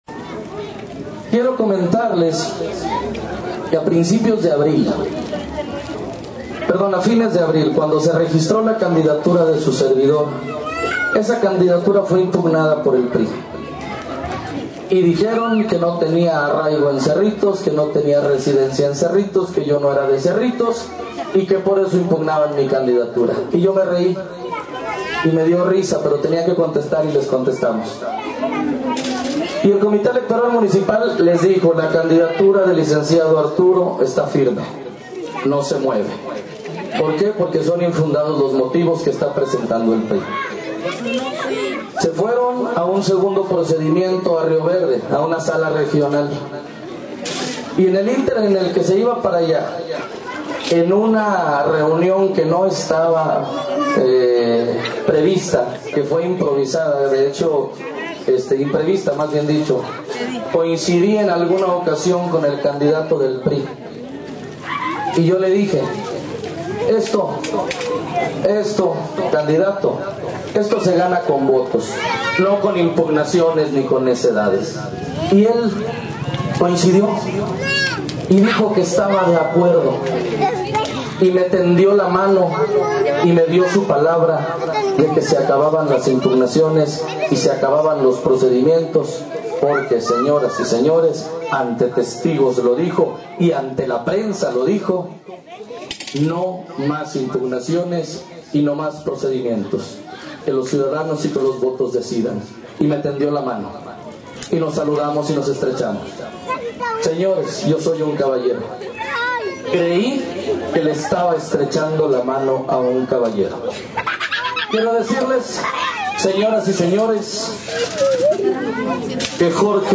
• Así lo manifestó ante vecinos de Padre Jesús